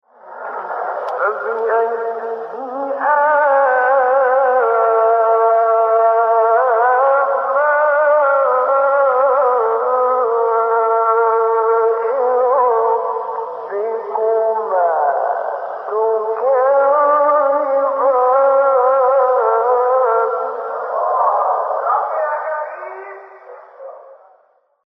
گروه شبکه اجتماعی: 10 مقطع صوتی از قاریان برجسته مصری که در مقام رست اجرا شده‌ است، می‌شنوید.
مقام رست